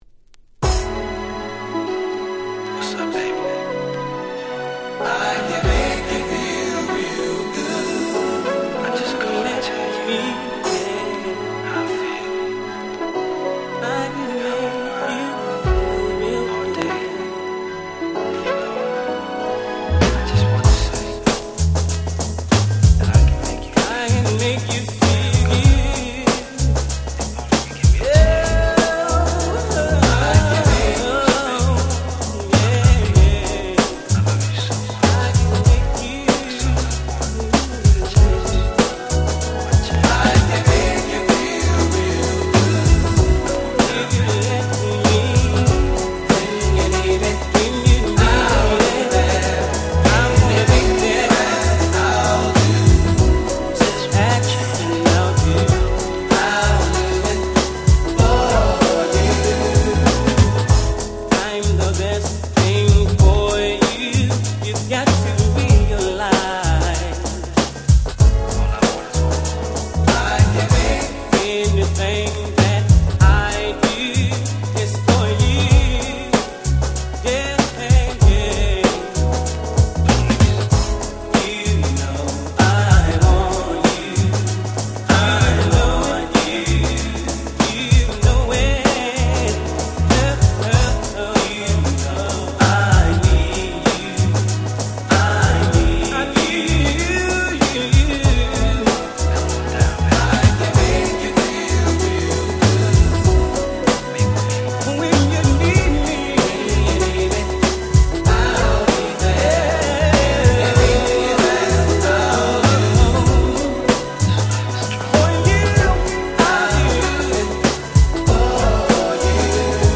94' Nice R&B / Hip Hop Album。